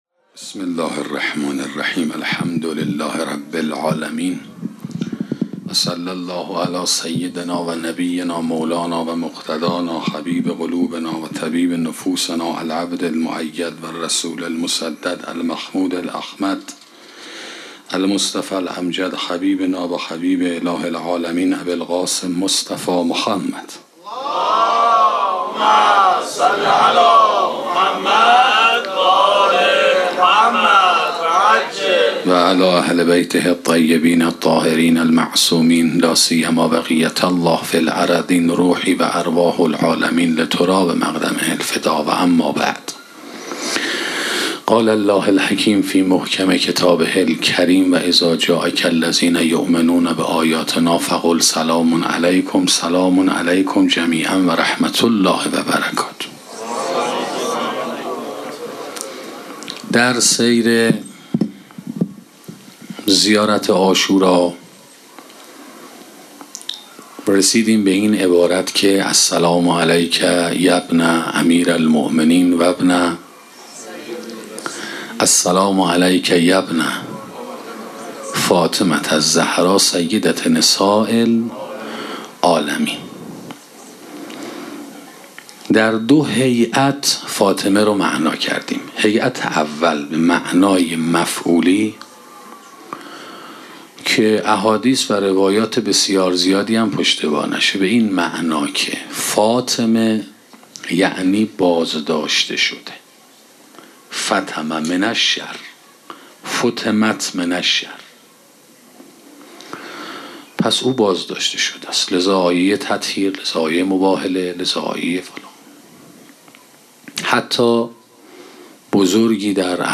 سخنرانی جایگاه معنوی مادر 7 - موسسه مودت